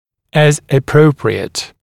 [əz ə’prəuprɪət][эз э’проуприэт]при необходимости; как требуется